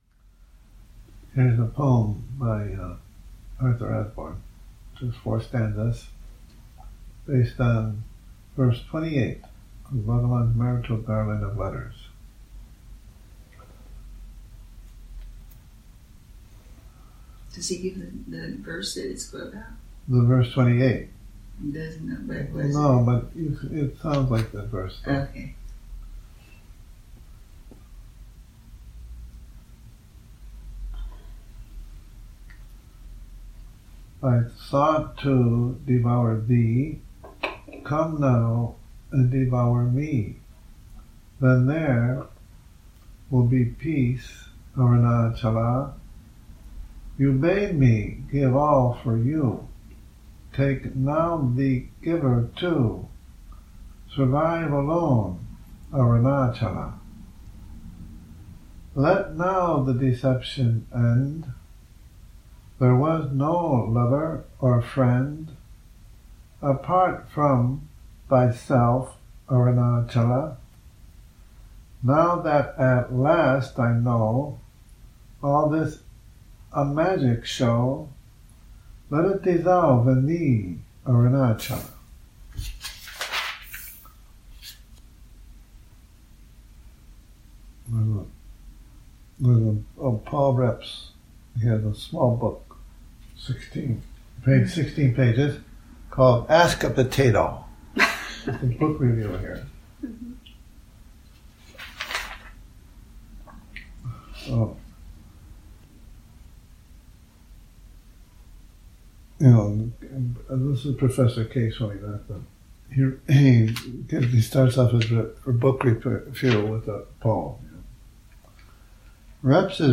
Morning Reading, 26 Nov 2019
a reading from the July 1967 issue of 'The Mountain Path', pp.240-262,